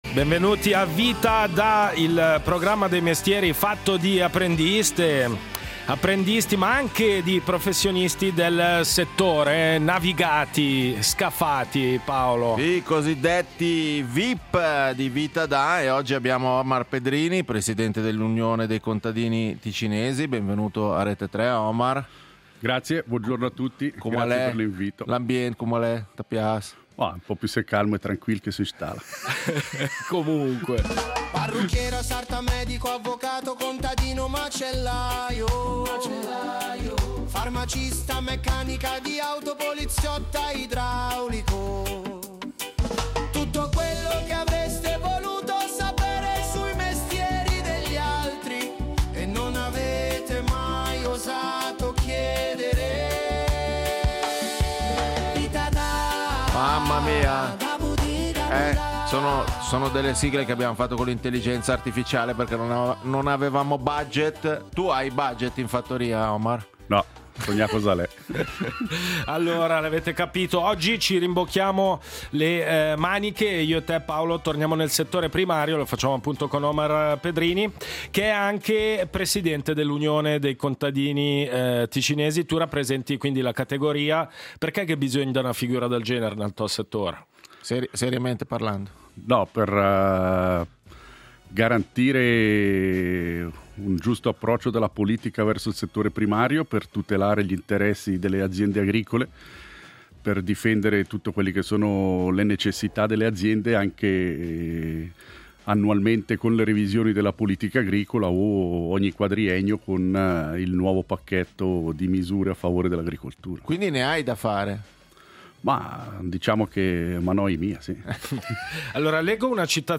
Ospite in studio: Omar Pedrini